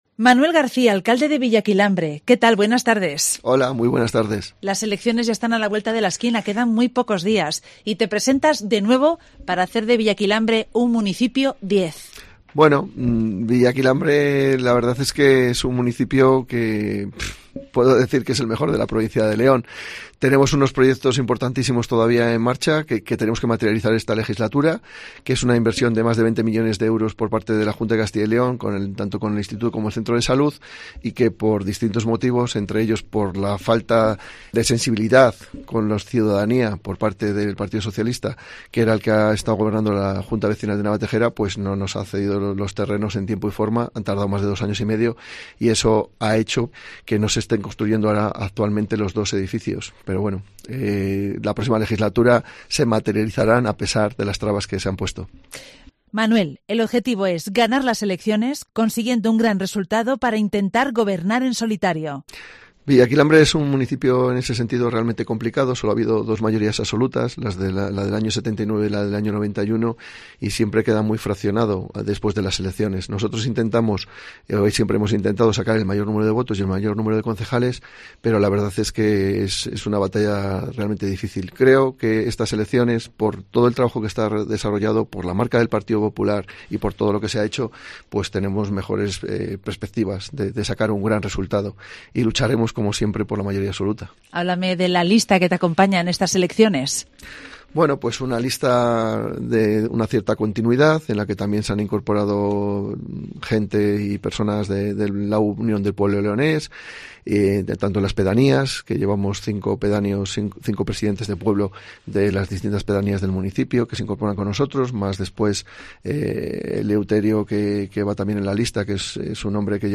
Manuel García, actual alcalde de Villaquilambre y candidato a la reelección por el Partido Popular en las elecciones municipales del próximo domingo 28 de mayo, ha visitado Cope León para pedir a los vecinos su voto.